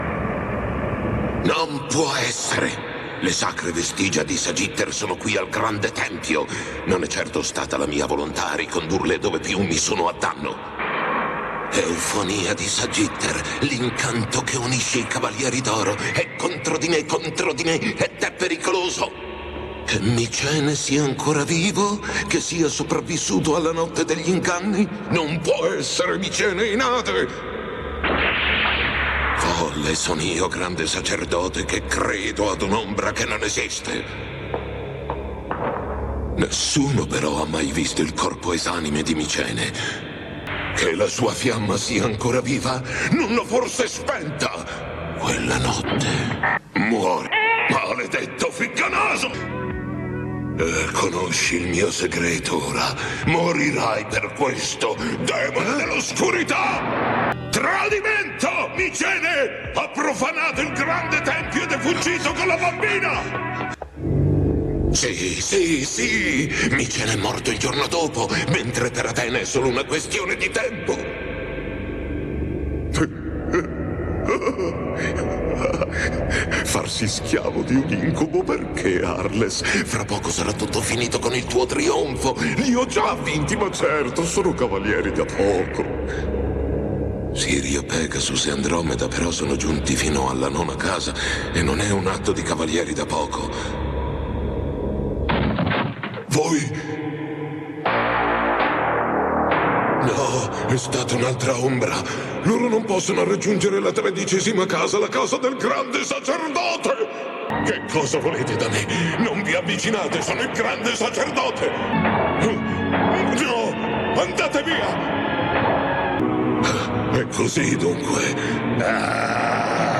nel cartone animato "I Cavalieri dello Zodiaco", in cui doppia Arles.